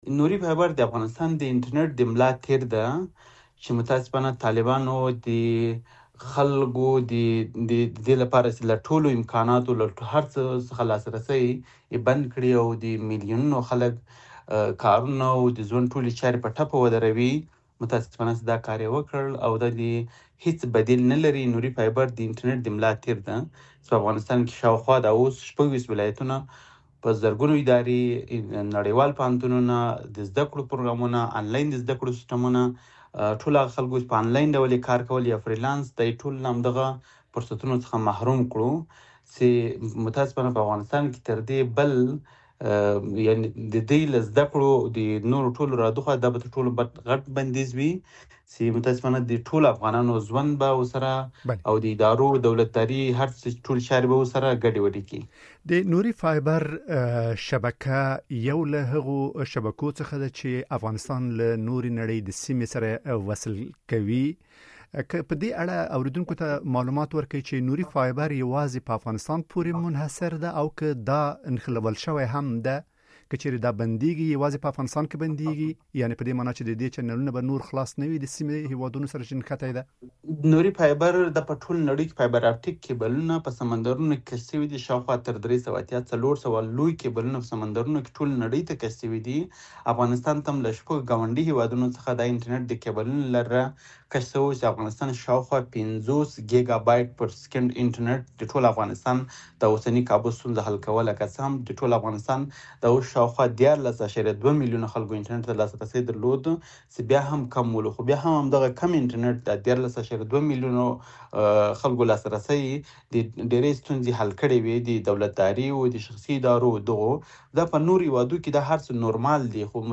مرکه - صدا